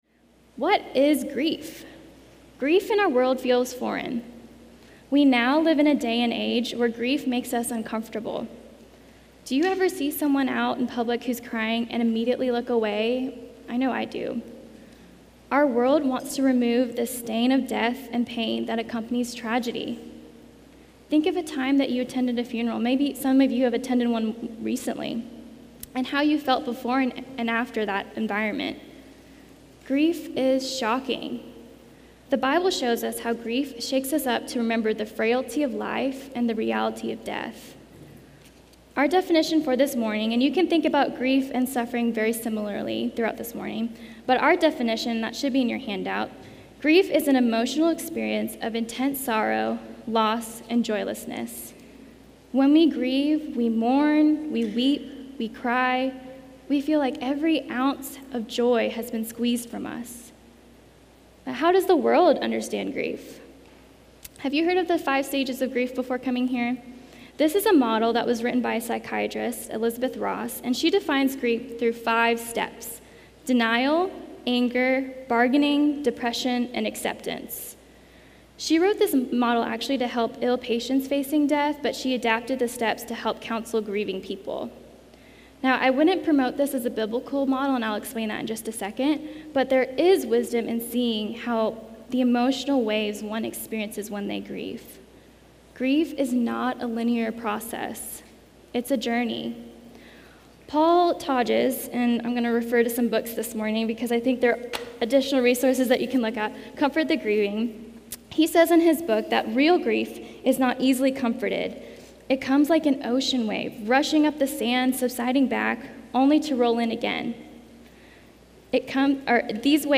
Audio recorded at MVBC’s 2022 Women’s Counseling Conference.